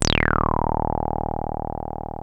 303 D#1 4.wav